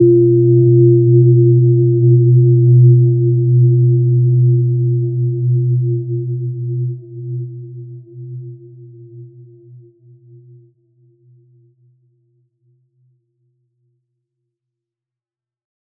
Gentle-Metallic-4-B2-mf.wav